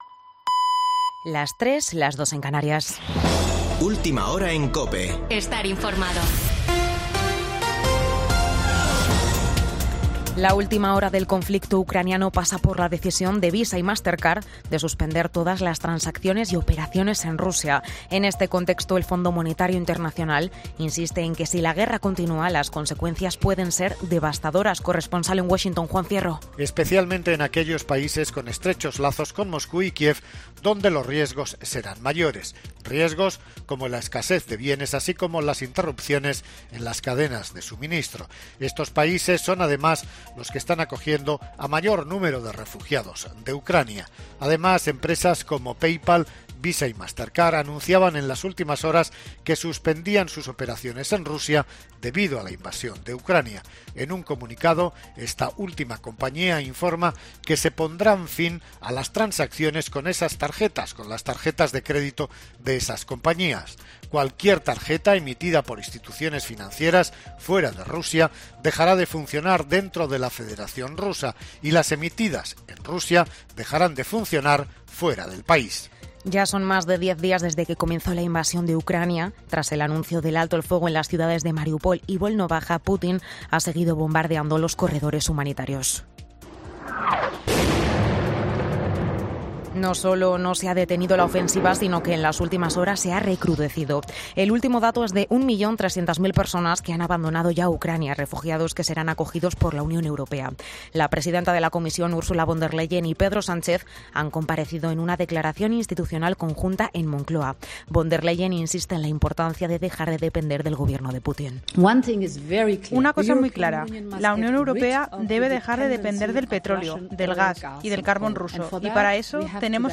Boletín de noticias COPE del 6 de marzo de 2022 a las 3.00 horas